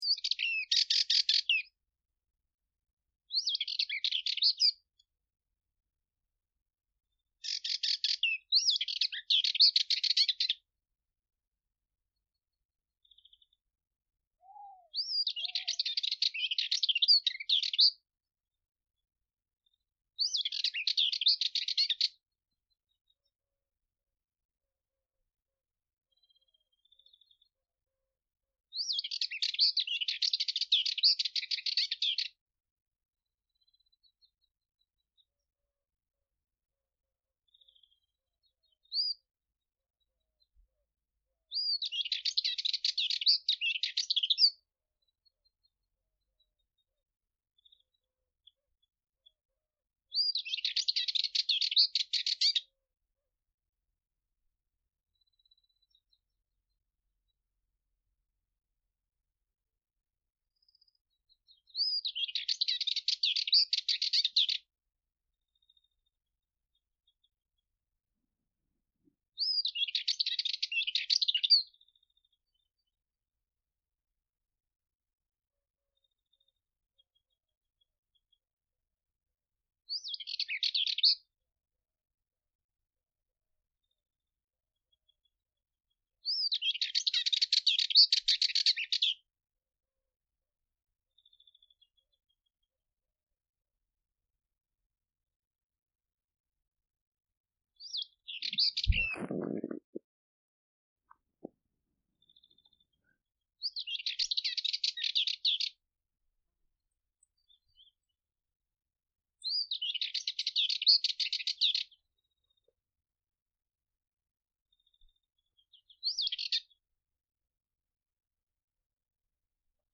(Sylvia melanocephala)
Occhiocotto-Sylvia-melanocephala.mp3